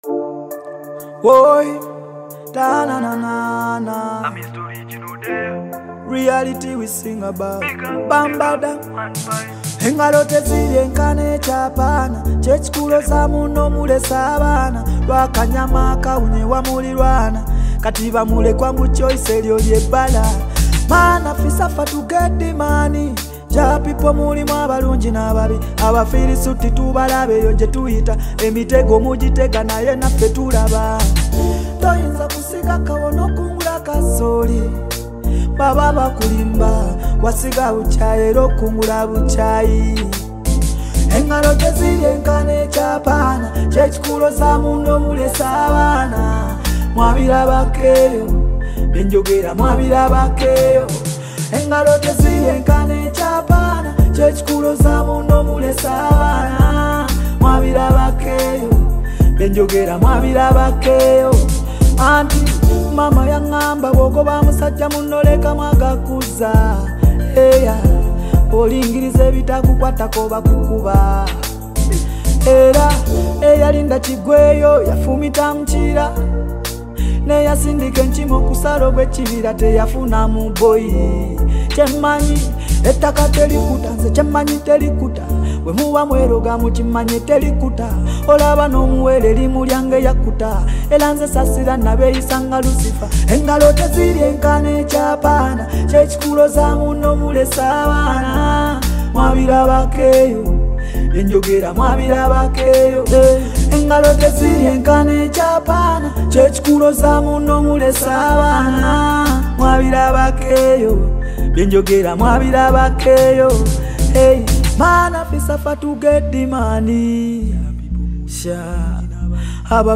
uganda vocal singer and songwriter